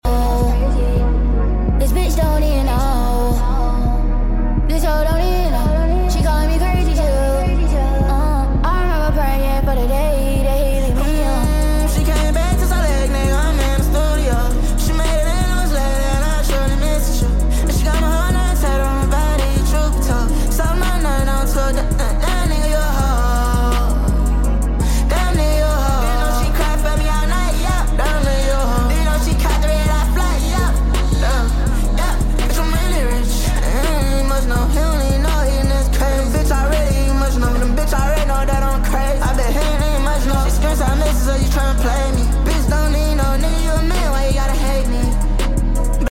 sped up